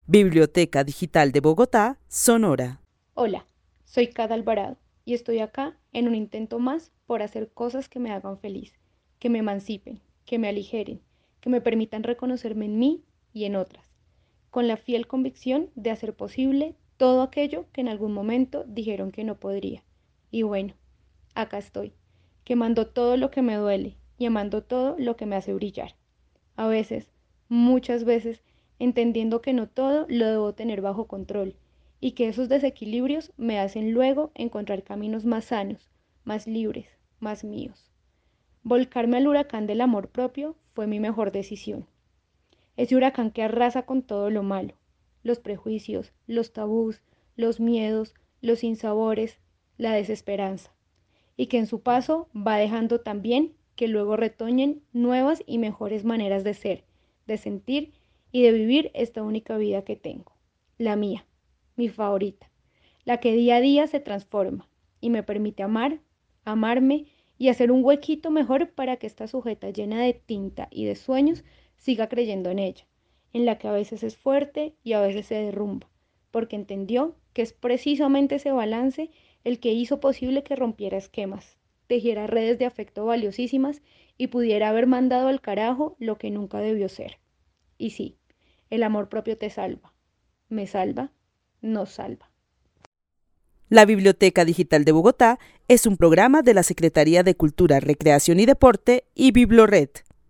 Narración oral de una mujer que vive en la ciudad de Bogotá y para quien el amor propio le permite reconocerse en ella y en las demás: le permite hacer lo que un día le dijeron que no podría. Considera que el amor propio es huracán que arrasa con los prejuicios, tabúes, y miedos para luego generar nuevas formas de ser.